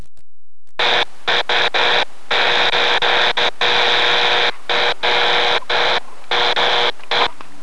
Power Line RFI
AM Radio Powerline RFI audio
Powerline noise AM radio.wav